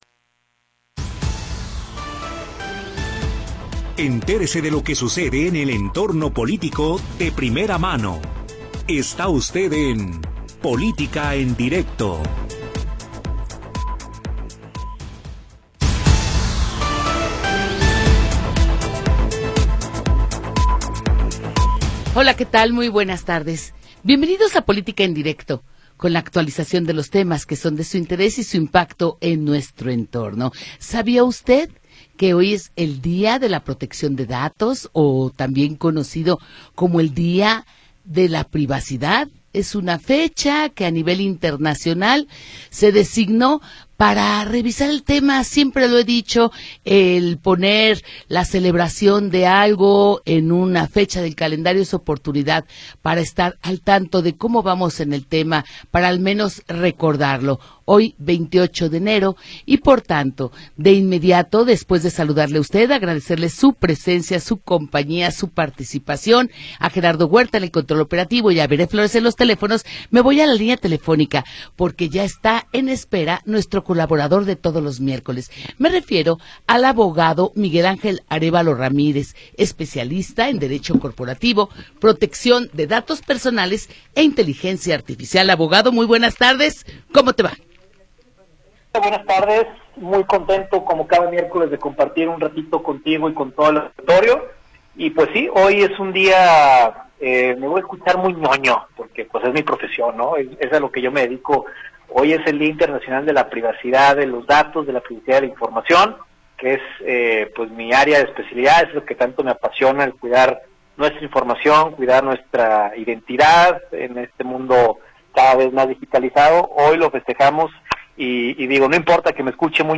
Entérese de todo lo que sucede en el entorno político. Comentarios, entrevistas, análisis y todo lo que a usted le interesa saber